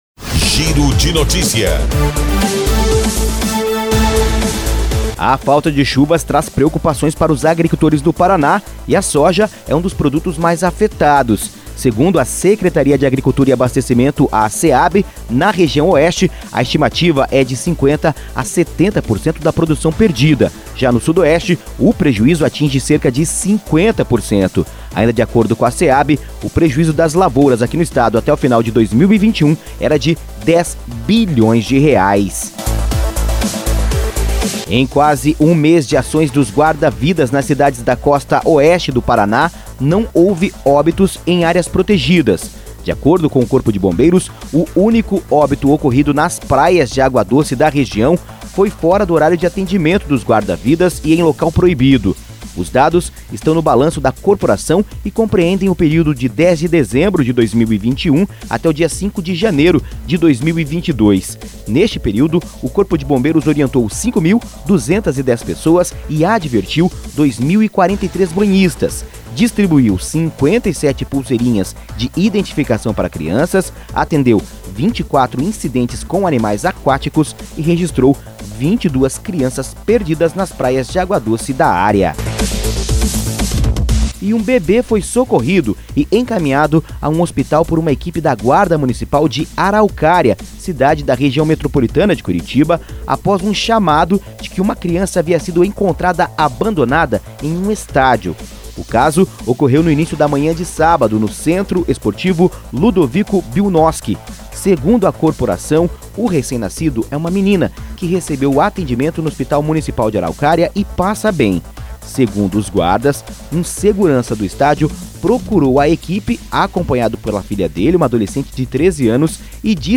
Associação das Emissoras de Radiodifusão do Paraná